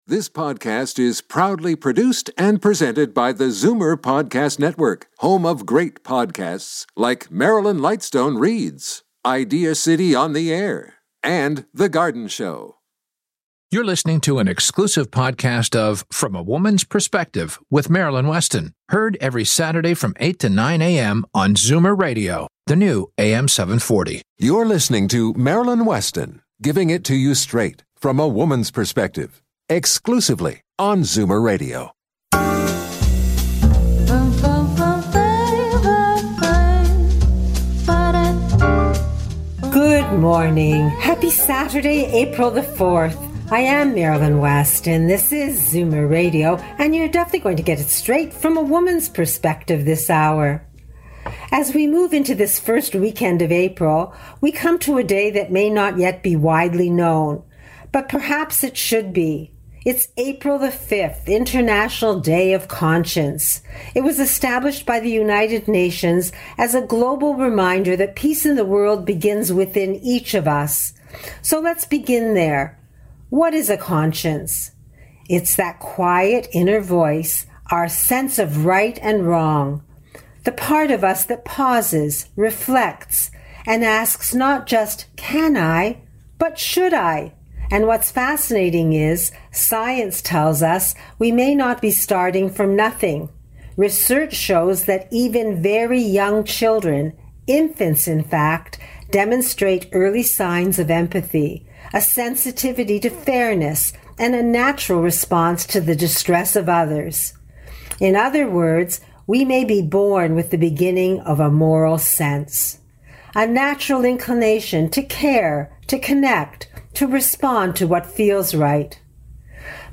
Every Saturday from 8-9am on Zoomer Radio AM740 and FM96.7 Be a guest on this podcast Language: en Genres: Health & Fitness , Science Contact email: Get it Feed URL: Get it iTunes ID: Get it Get all podcast data Listen Now... Make a Difference Day!